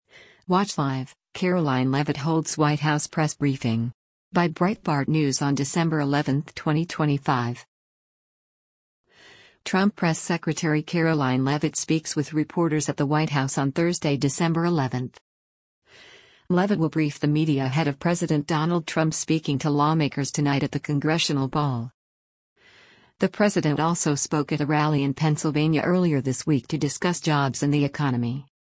Trump Press Secretary Karoline Leavitt speaks with reporters at the White House on Thursday, December 11.